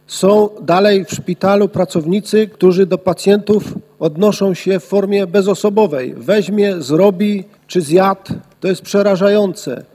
Słowa krytyki padły również o zmianę w podejściu do opieki nad pacjentami , apelował radny Kamiński.